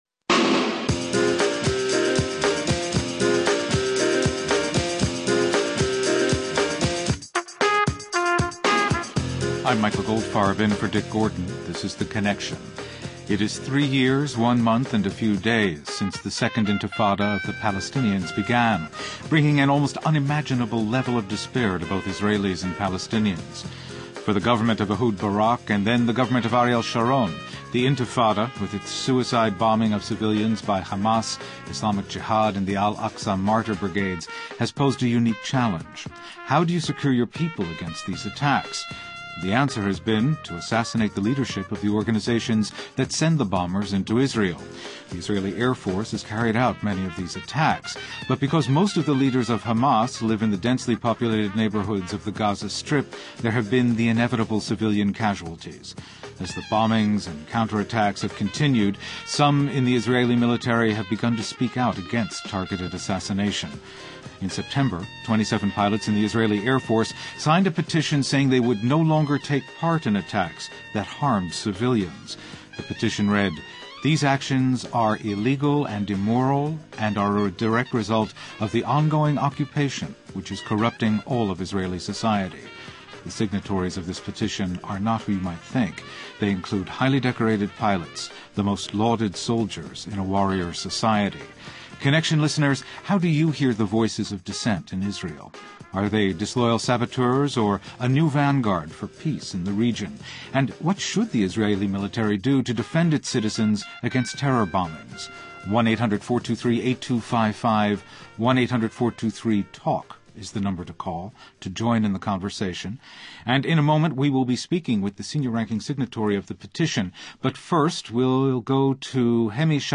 Israeli General Speaks on War and Peace